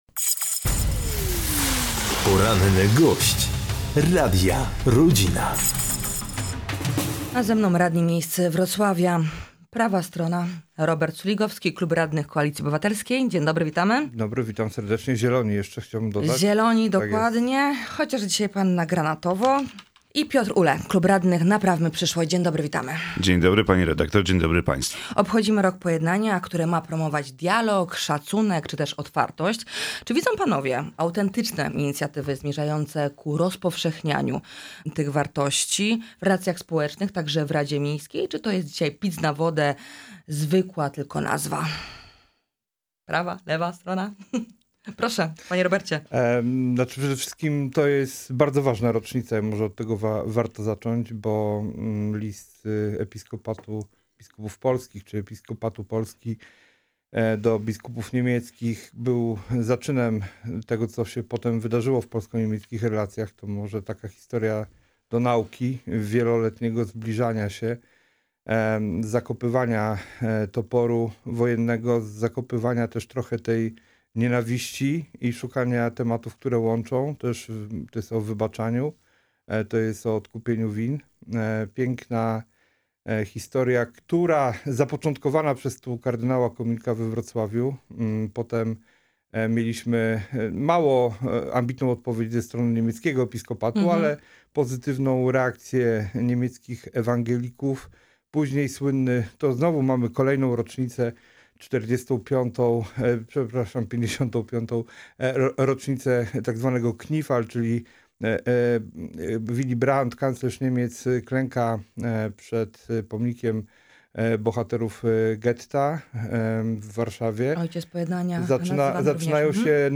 Piotr Uhle – z Klubu Radnych Naprawmy Przyszłość i Robert Suligowski z Klubu Radnych Koalicji Obywatelskiej, partia Zieloni byli gośćmi w audycji „Poranny Gość”. Z radnymi poruszyliśmy tematy związane z Rokiem Pojednania, drugą próbą zwołania referendum, a także lex deweloper.